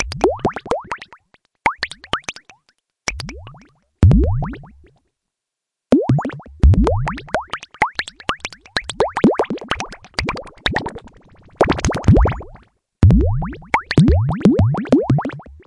开关 " 开关B
描述：设计用于游戏响应的短噪音，来自SwitchA的低音调。
标签： 噪声 合成器
声道立体声